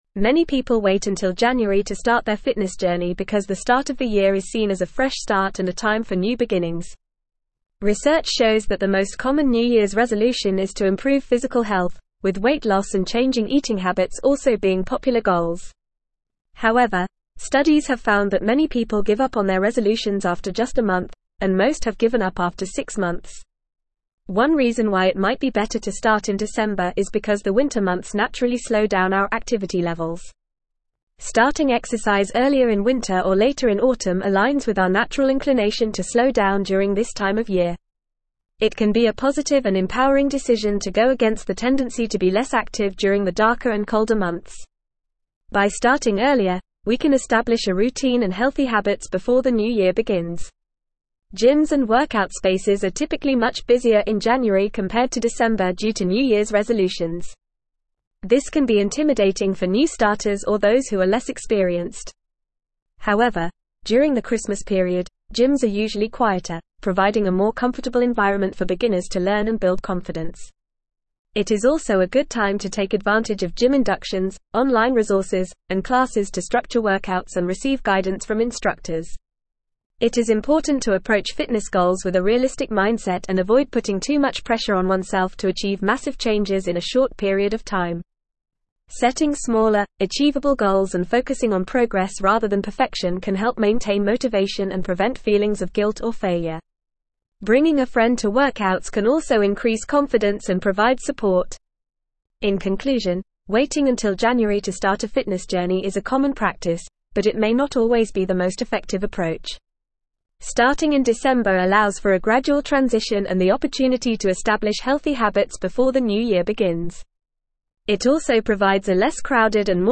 Fast
English-Newsroom-Advanced-FAST-Reading-Starting-Your-Fitness-Journey-Why-December-is-Ideal.mp3